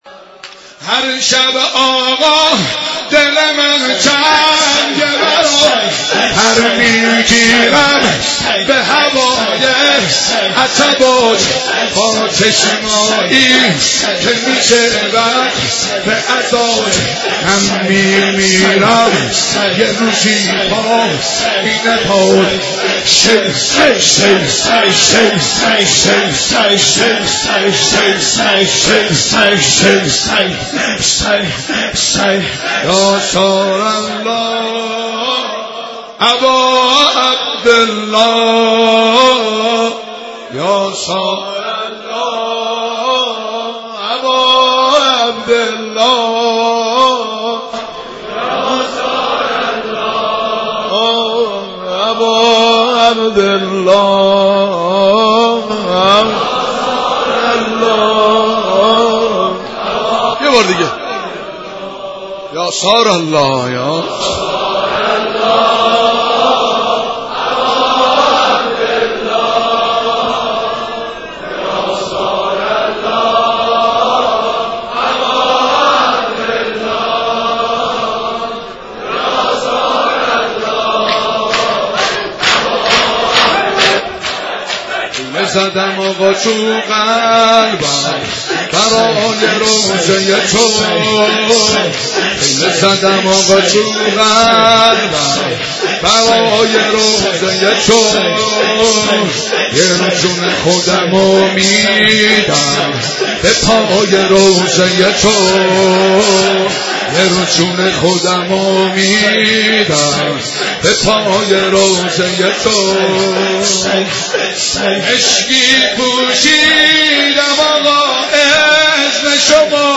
سینه زنی شور